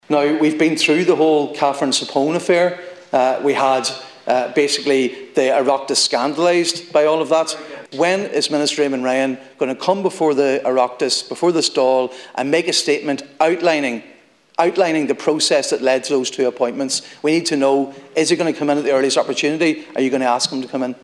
A Donegal Deputy has clashed in the Dail with Taoiseach Michael Martin after the Environment Minister Eamon Ryan appointed ‘two close colleagues’ as members of the Climate Change Advisory Council.
Speaking in the Dail Deputy Padraig Mac Lochlainn says that no formal public appointments have been made: